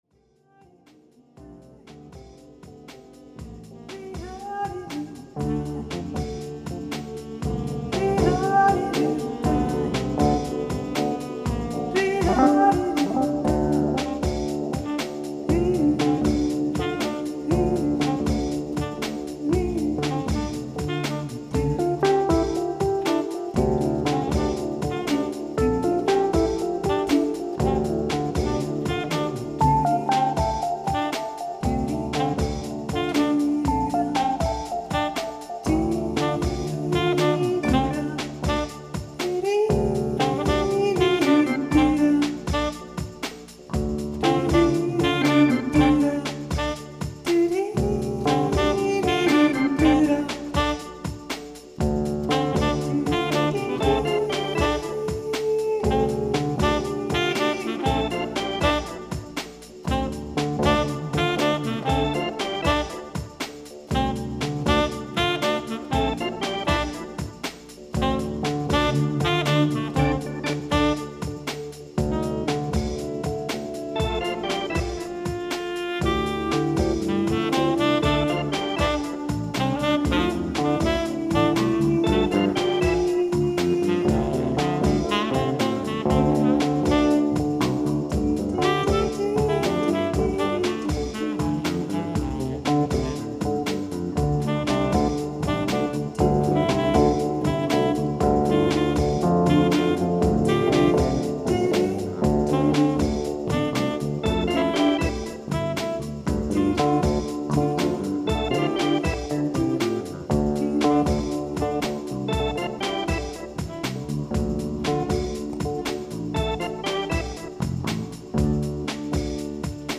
Tempo: 80 bpm / Date: 23.06.2018